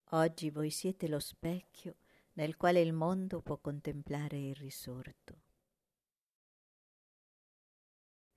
Preghiera mp3